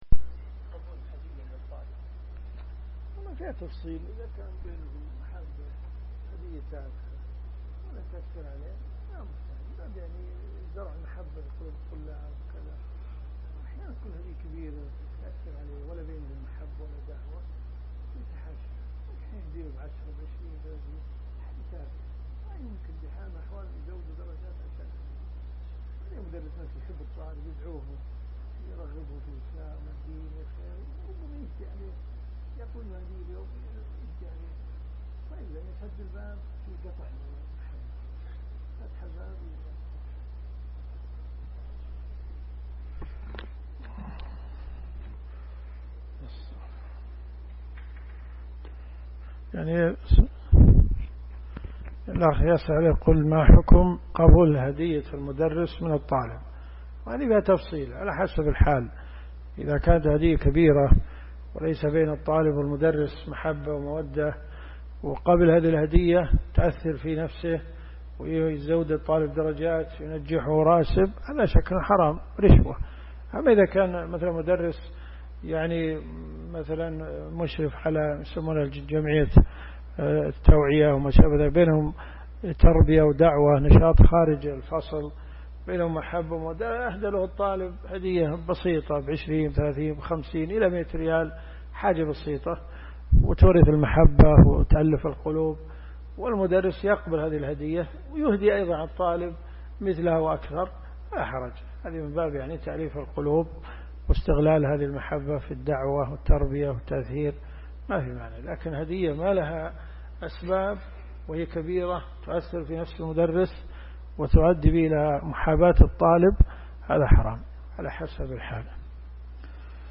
الرئيسية الكتب المسموعة [ قسم أحاديث في الفقه ] > المنتقى من أخبار المصطفى .